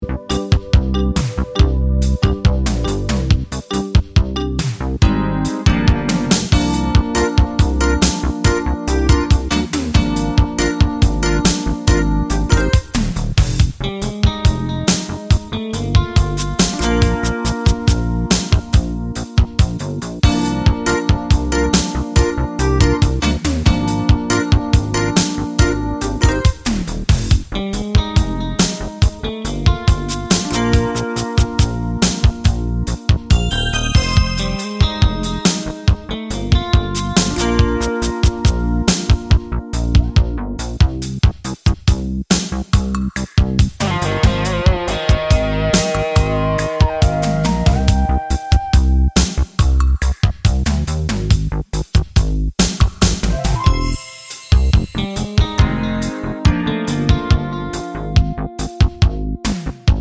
(With BVs) - Two Semitones Up Soul / Motown 4:21 Buy £1.50